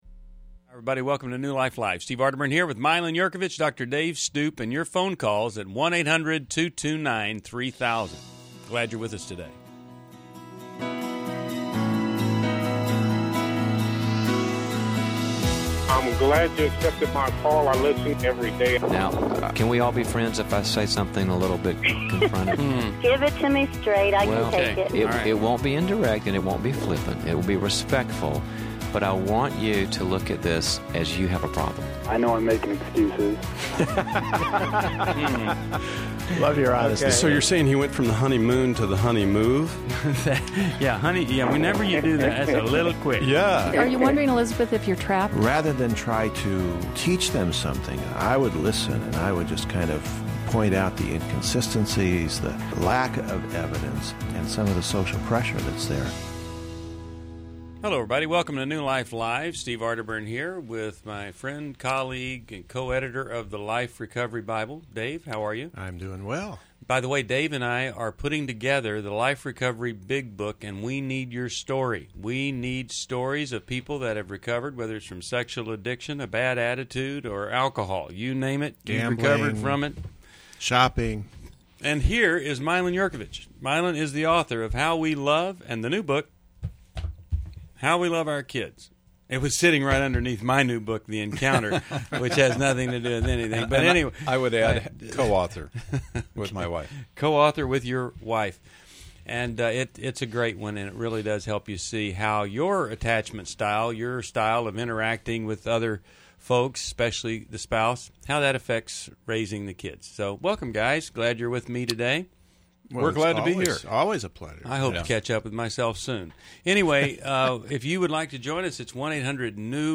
Caller Questions: 1. How can I make my long-distance dating relationship work? 2.